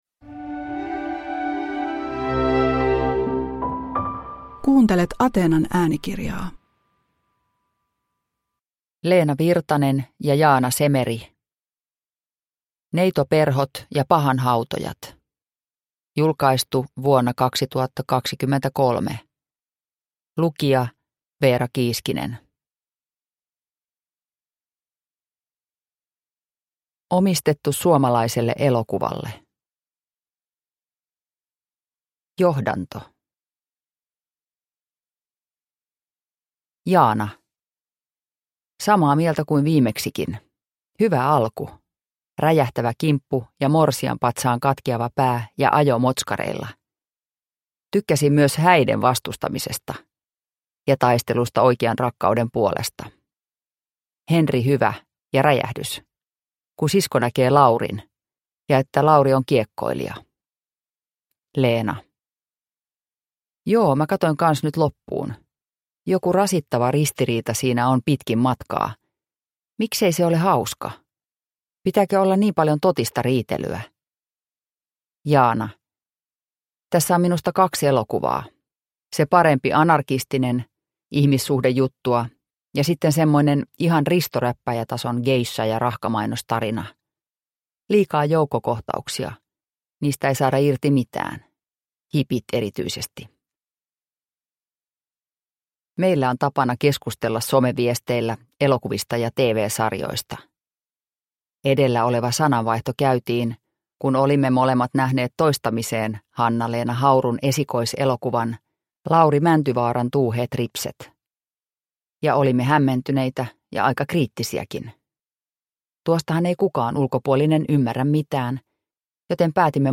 Neitoperhot ja pahanhautojat – Ljudbok